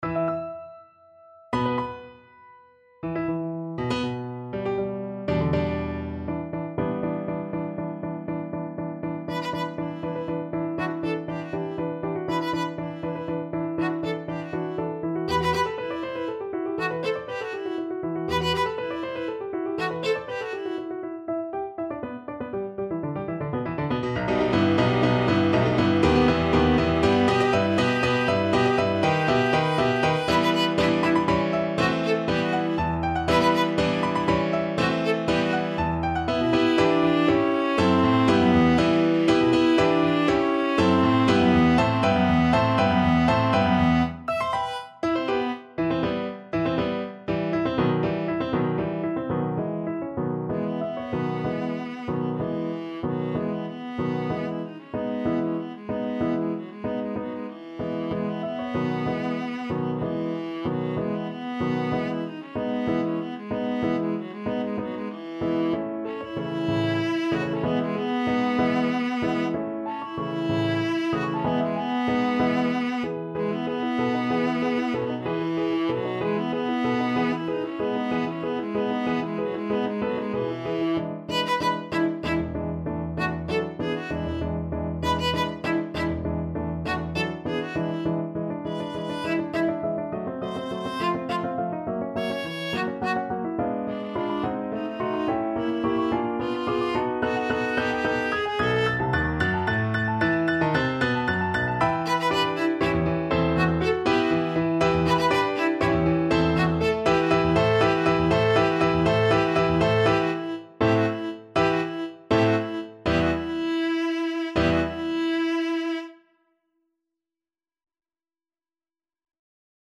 Viola version
3/4 (View more 3/4 Music)
Molto vivace .=80
Classical (View more Classical Viola Music)